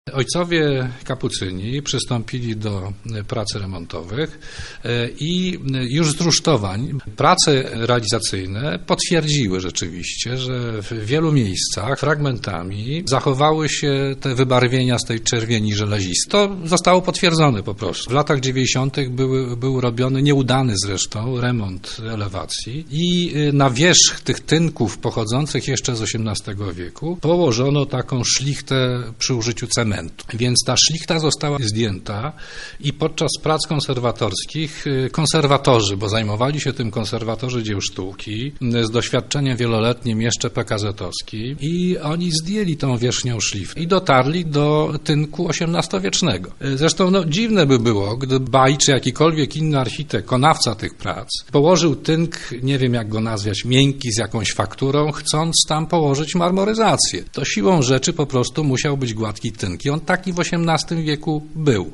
O tym, jak został wybrany kolor i jak przybiegała renowacja, mówi Dariusz Kopciowski, lubelski konserwator zabytków.